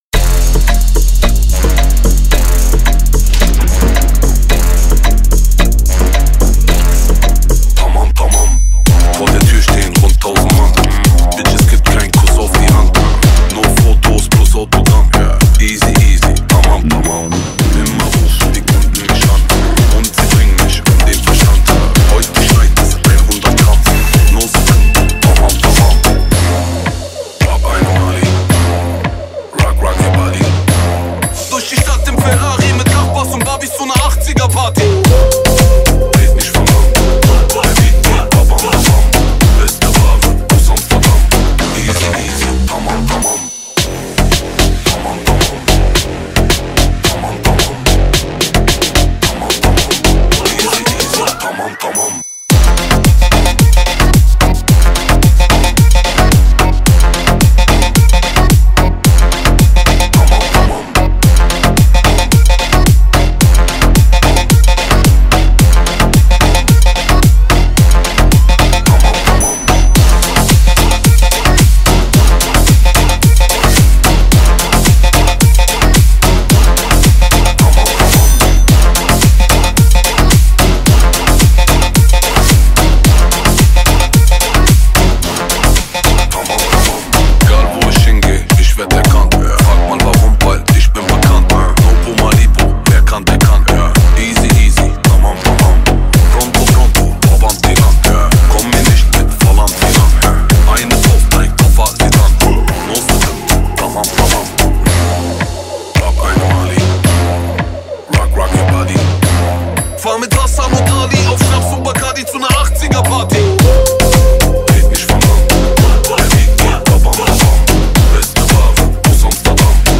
Download shuffle remix for dance